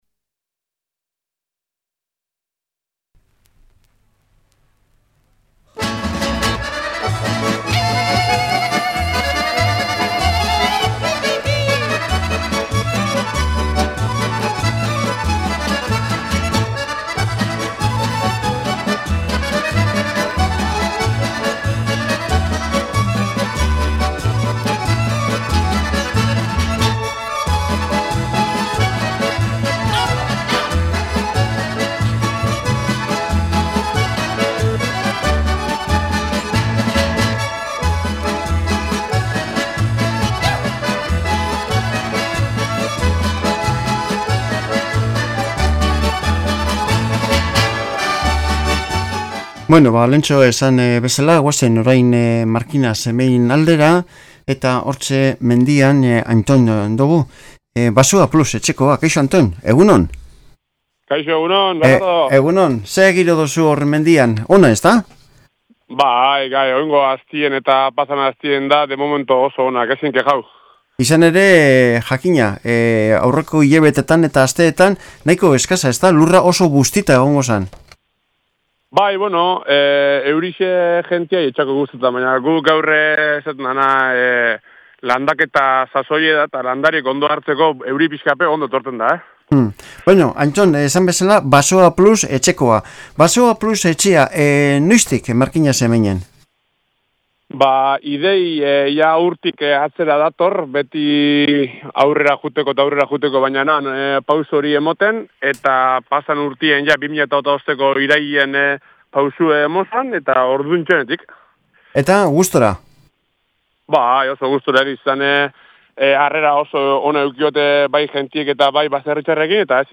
Alkarrizketa Basoaplus 26-03-03 Reproducir episodio Pausar episodio Mute/Unmute Episode Rebobinar 10 segundos 1x Fast Forward 30 seconds 00:00 / 00:23:20 Suscribir Compartir Feed RSS Compartir Enlace Incrustar